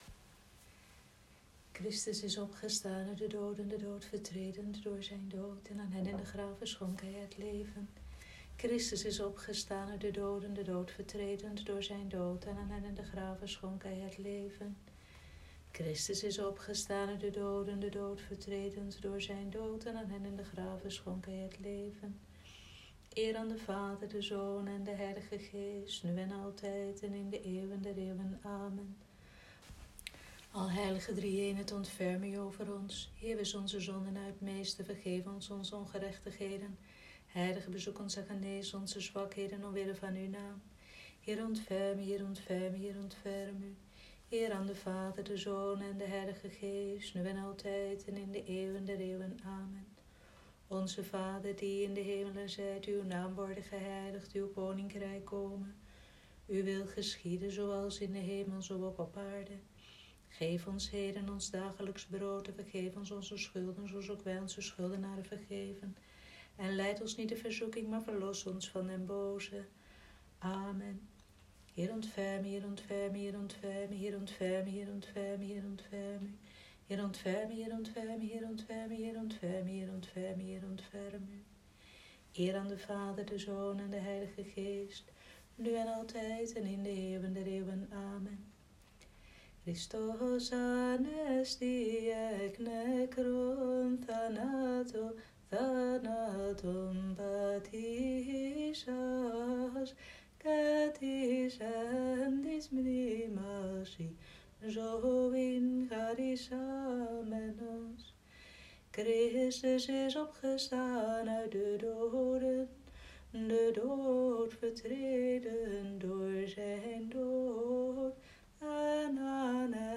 Vespers Middenpinksteren, 12 mei 2020
Vespers-Middenpinksteren.m4a